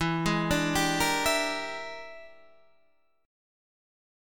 E 7th Sharp 9th Flat 5th